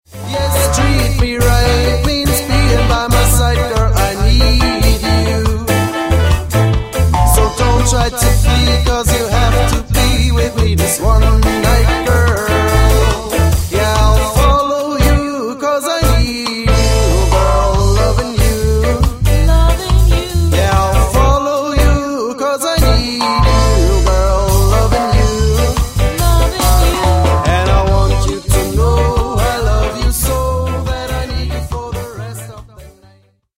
hinzu kommt ein Mix aus zwei Vocals.